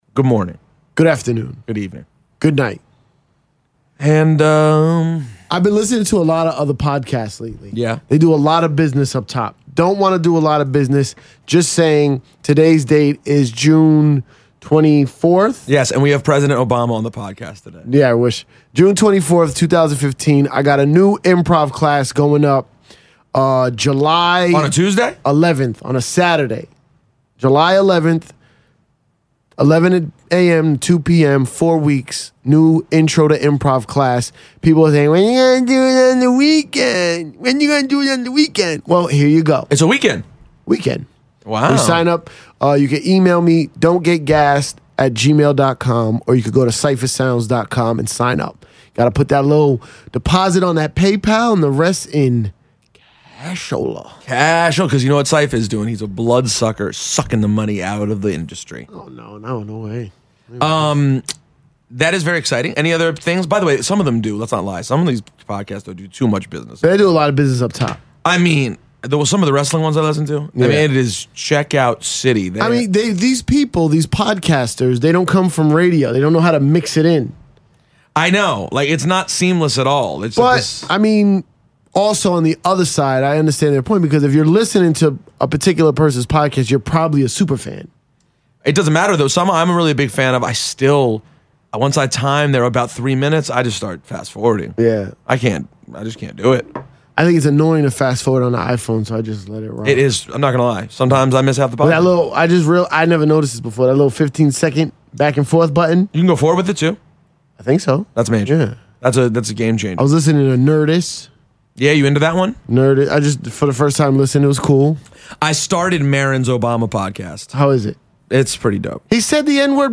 And we only got one friend on the phone, but it was Hannibal Burress.